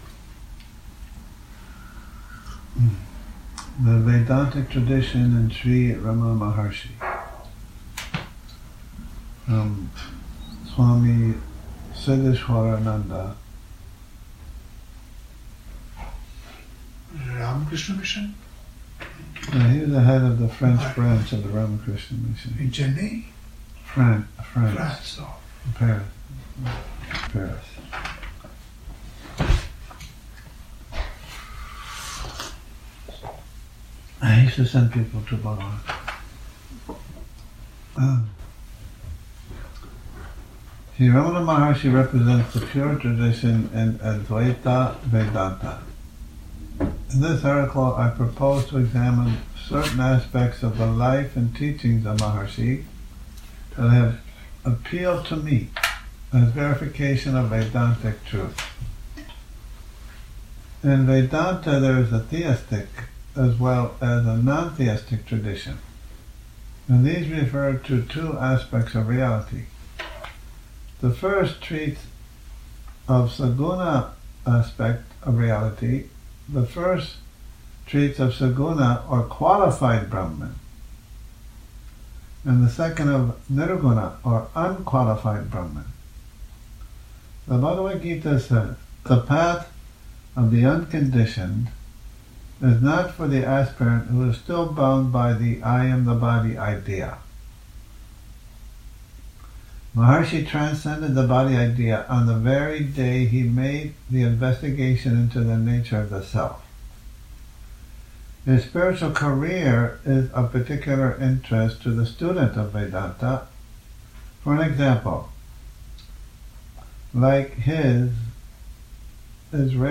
Morning Reading, 04 Nov 2019